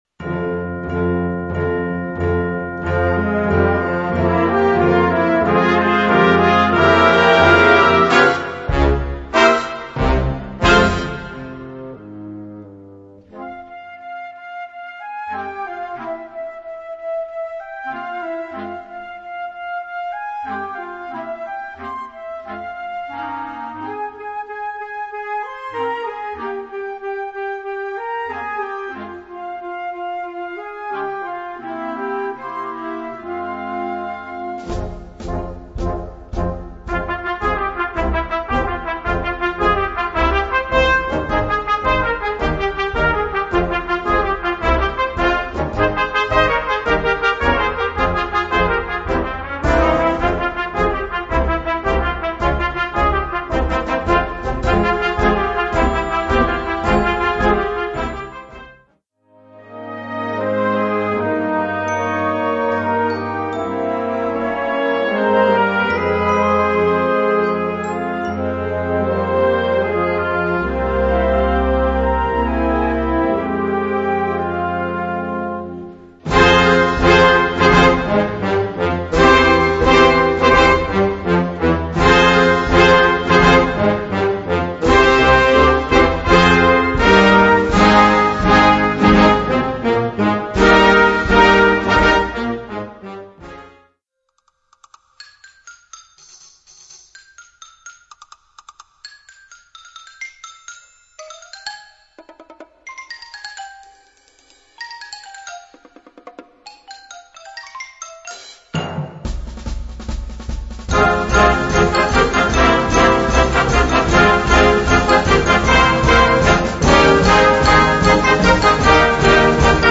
Category Concert/wind/brass band
Instrumentation Ha (concert/wind band)